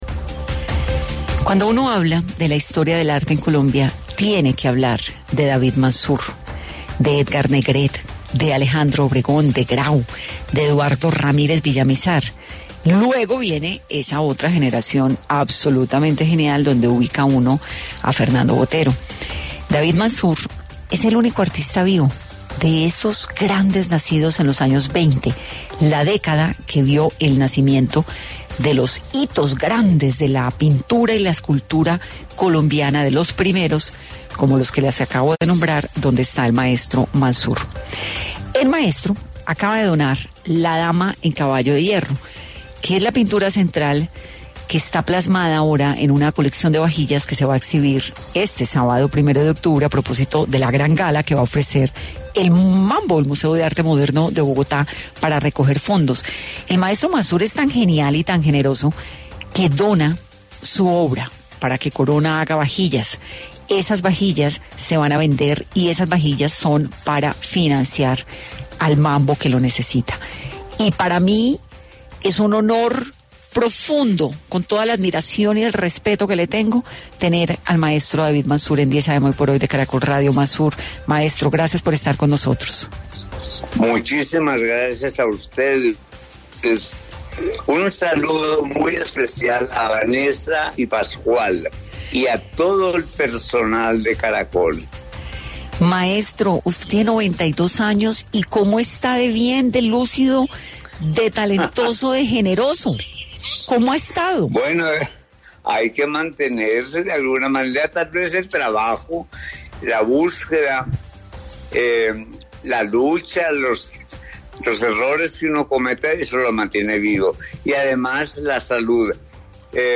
En entrevista con 10 AM hoy por hoy de Caracol Radio, el pintor habló de su trabajo y legado.